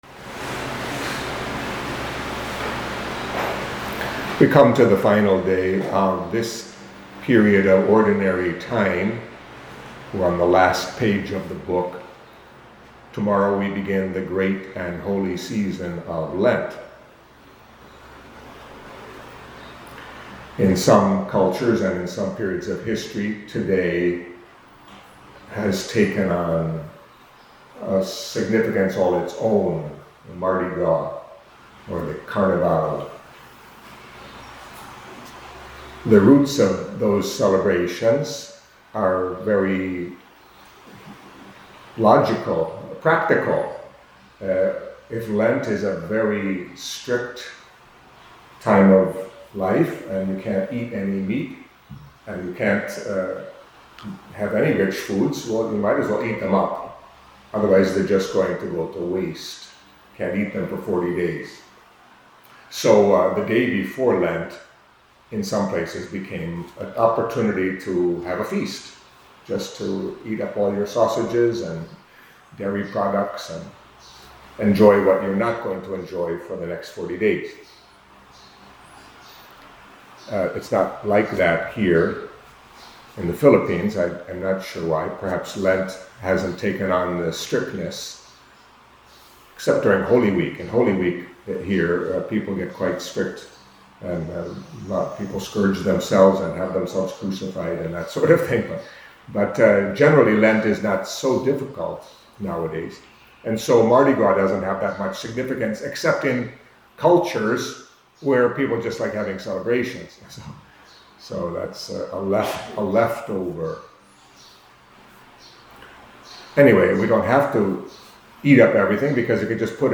Catholic Mass homily for Tuesday of the Sixth Week in Ordinary Time